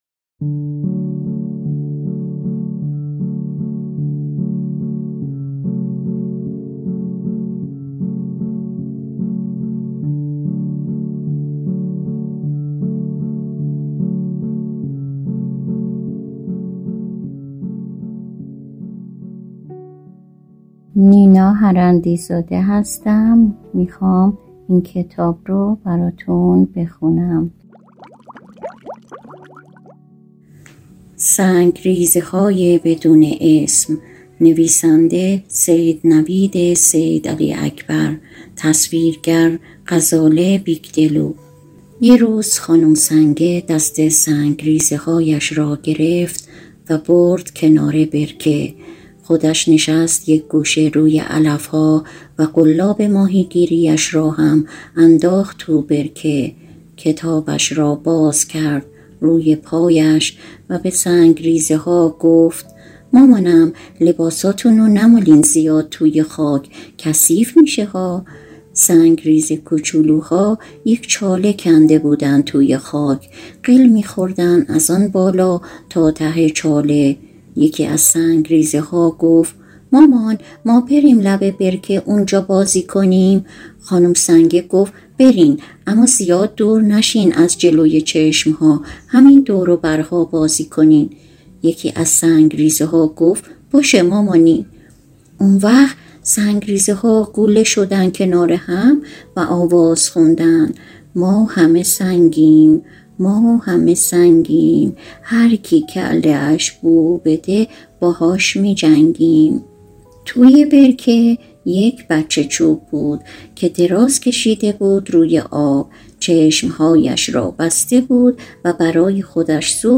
کتاب صوتی-سنگریزه‌های بدون اسم؛